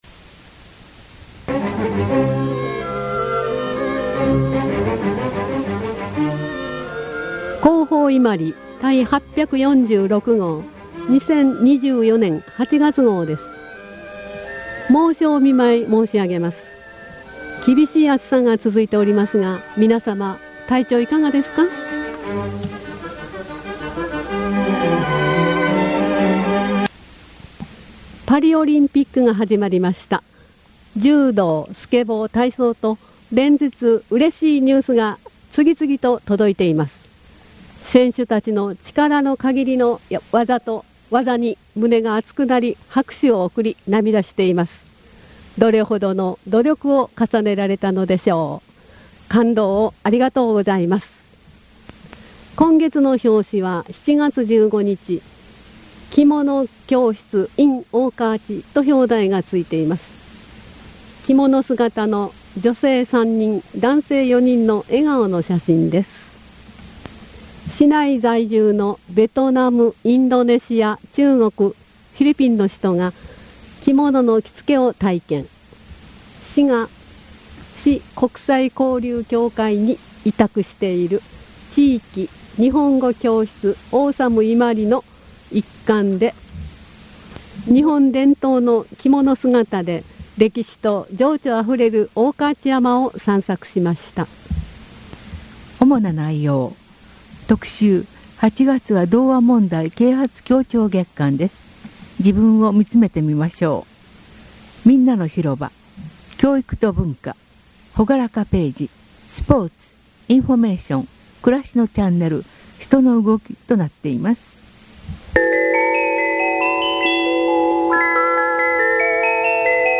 「声の広報伊万里」はこちらです。